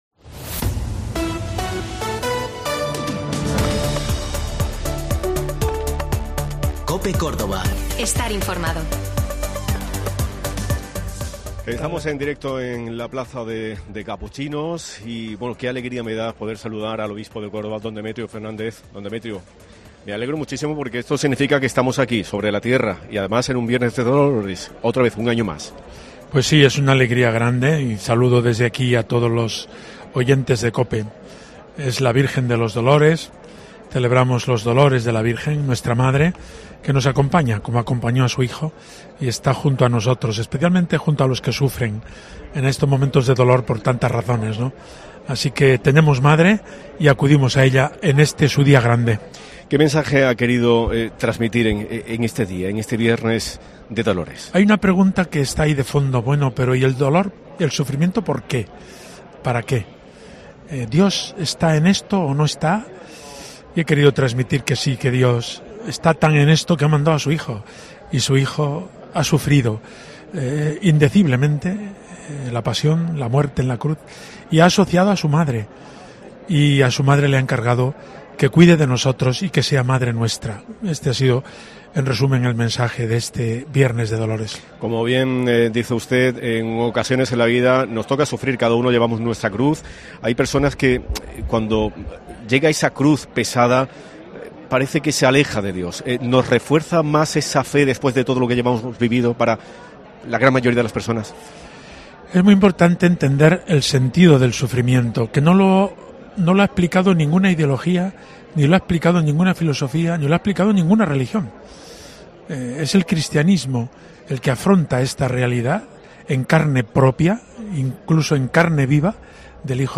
Don Demetrio Fernández, obispo de Córdoba